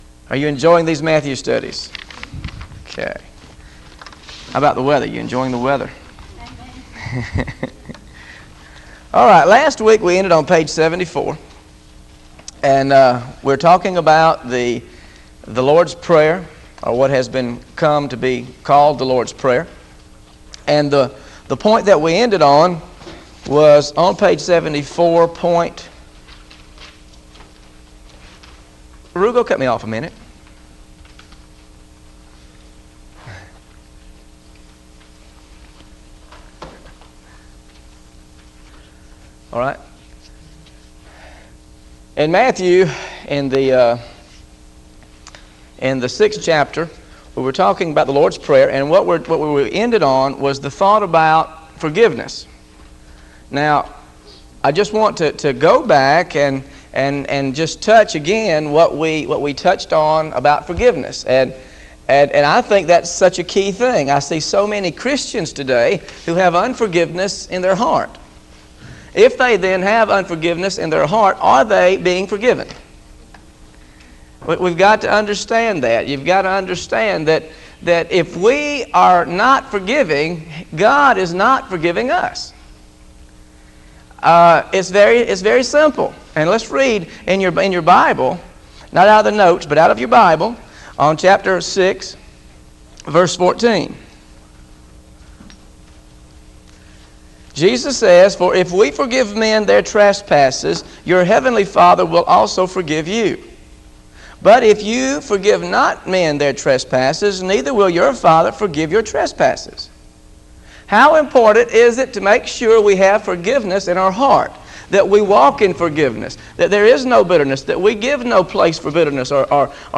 Listen to the Teaching on Matthew 6 13-24 Audio